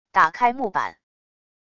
打开木板wav音频